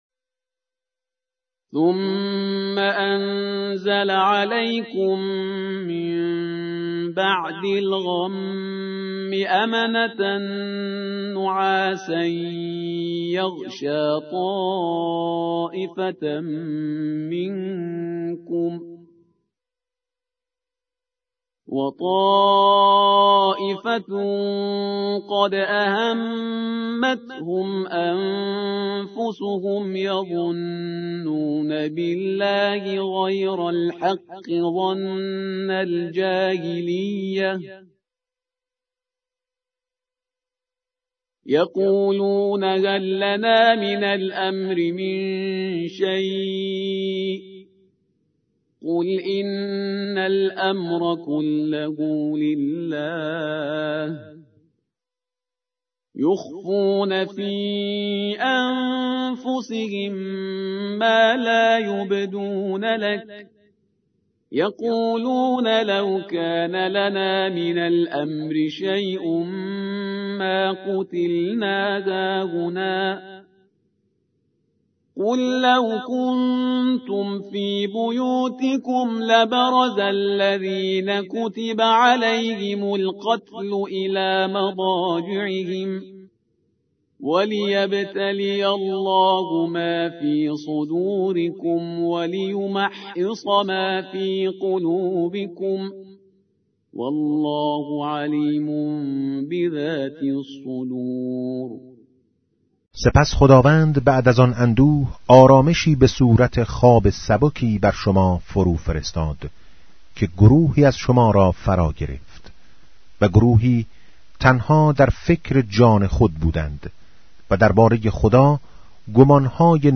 به گزارش آوای سیدجمال، از آنجا که پیامبر اکرم(ص)، در آخرین وصیت خود، از قرآن به‌عنوان ثقل اکبر یاد کرده و تأکید بر توجه به این سعادت بشری داشت،  بر آن شدیم در بخشی با عنوان «کلام نور» تلاوتی از چراغ پرفروغ قرآن كه تلألو آن دل‌های زنگار گرفته و غفلت زده را طراوتی دوباره می‌بخشد به صورت روزانه تقدیم مخاطبان خوب و همیشه همراه آوای سیدجمال کنیم.